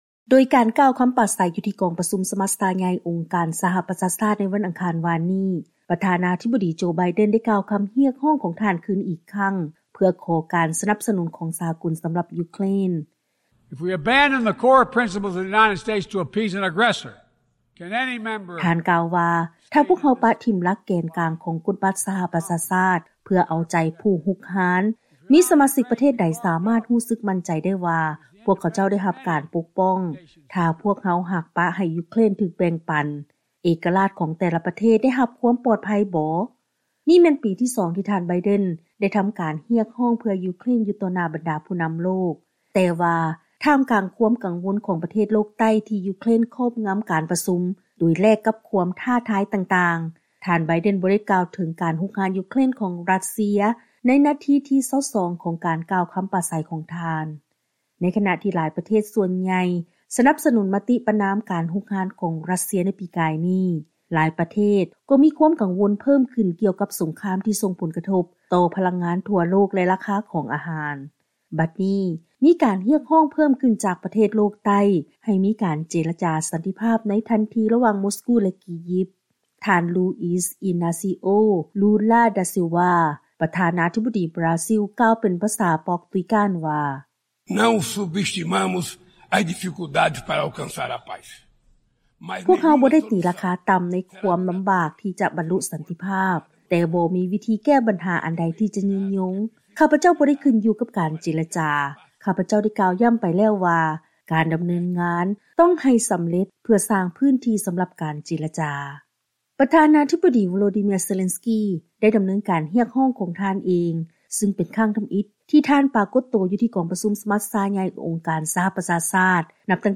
Luiz Inacio Lula da Silva, President of Brazil, male in Portuguese